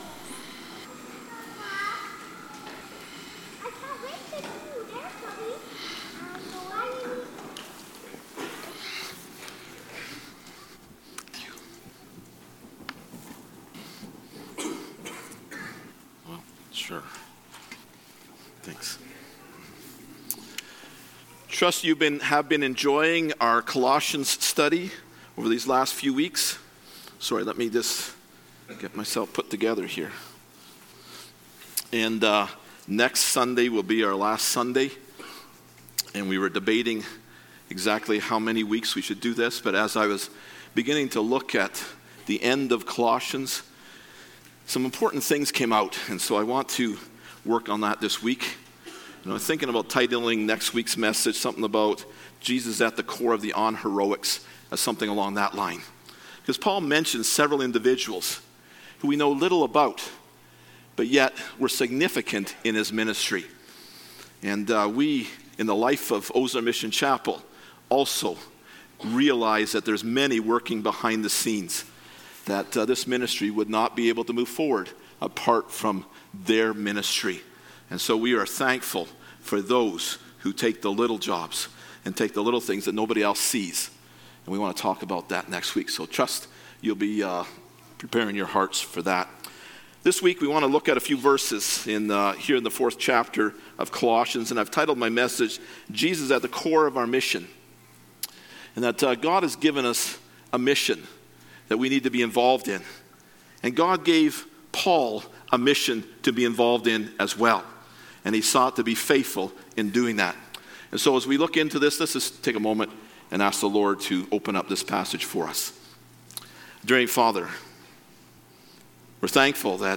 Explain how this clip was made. Passage: Colossians 4:2-6 Service Type: Sunday Morning